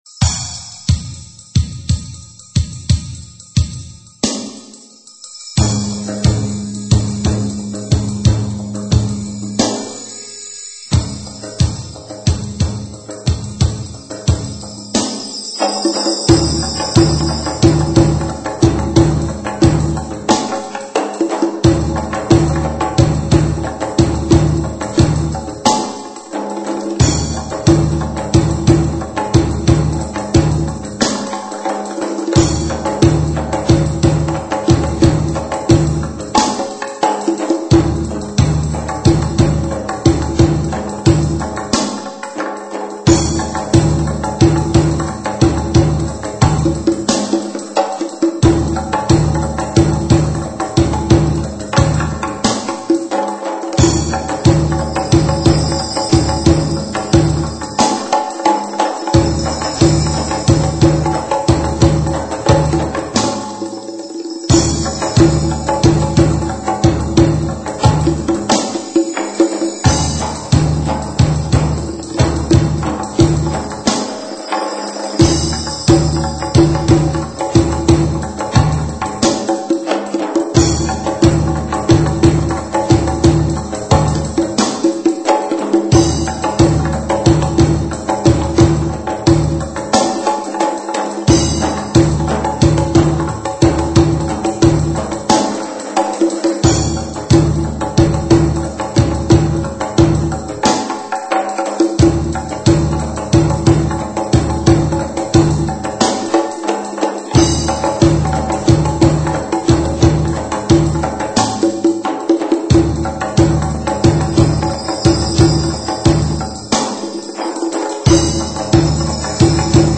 Bienvenue au site des amateurs de Mezoued Tunisien